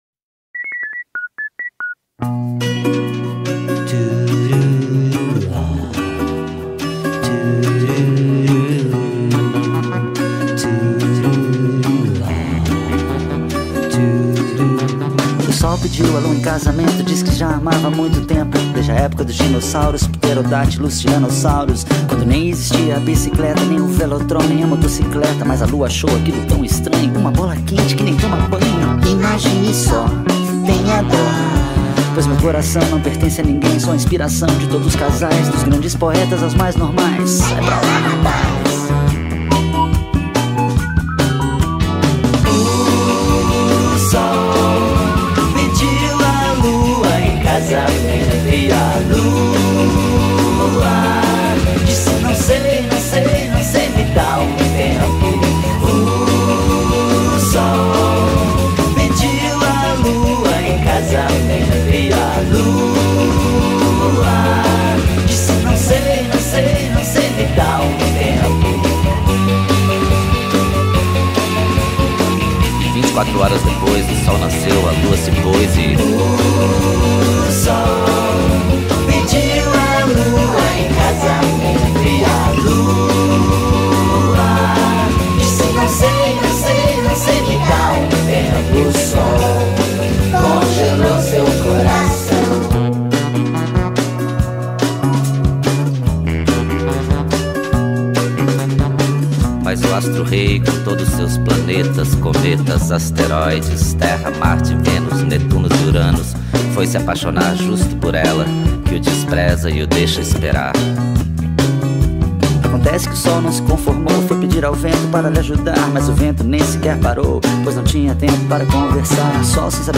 2025-02-23 01:05:07 Gênero: MPB Views